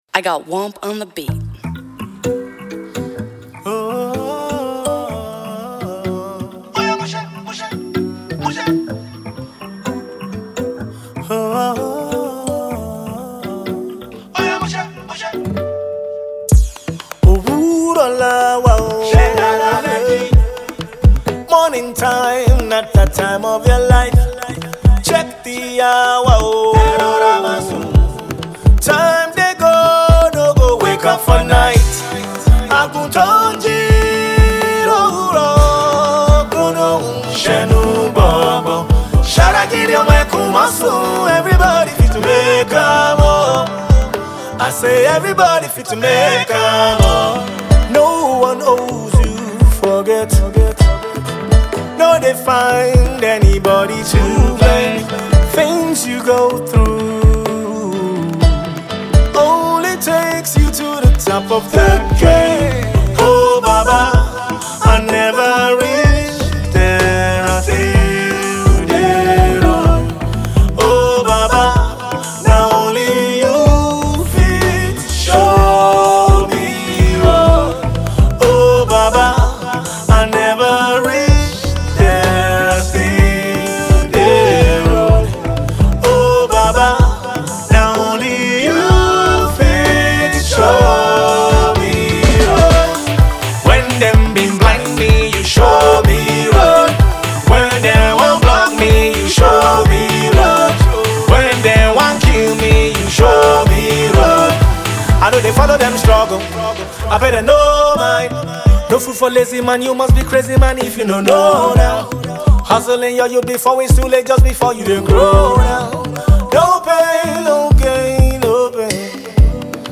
an inspirational track